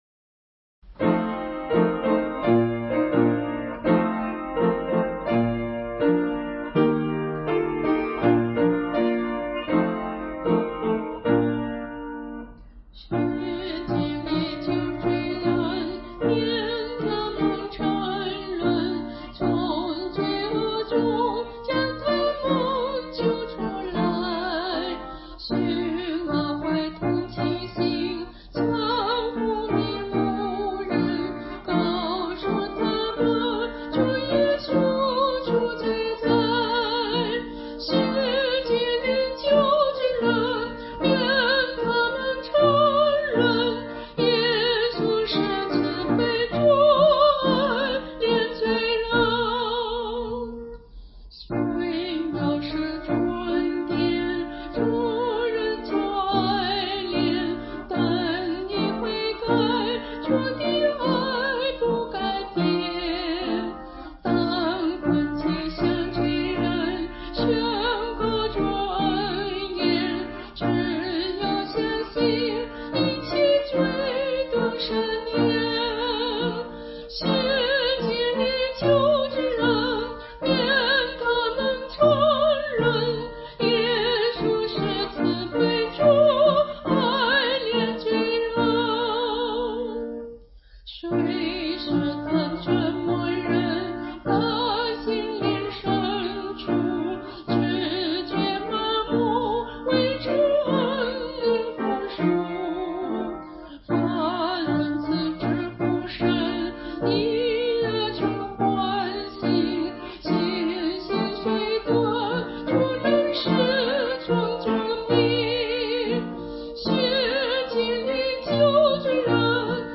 导读：本颂赞诗歌歌谱采用2017年修订版，录音示范暂用旧版，将逐渐更新。
伴奏